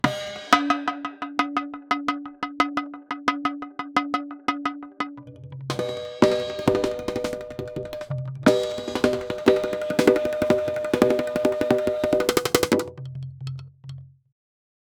duduk-and-arabic-drums-an-raeeexrn.wav